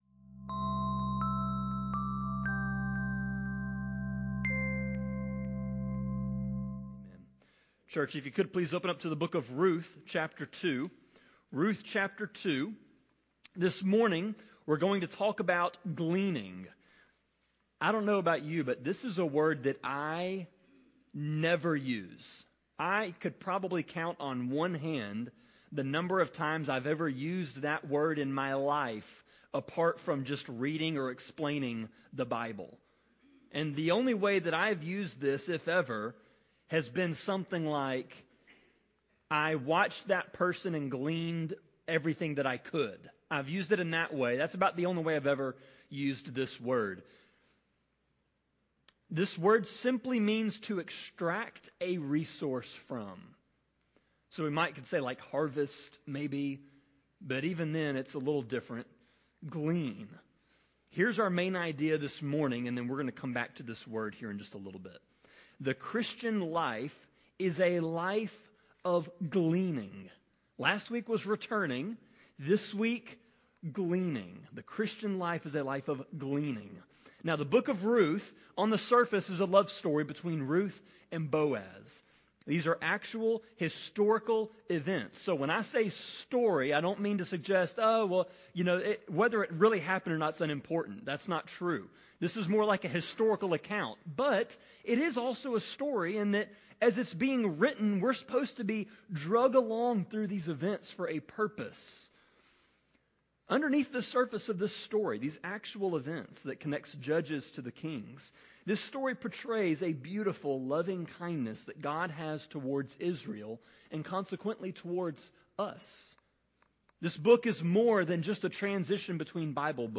Sermon-26.3.22-CD.mp3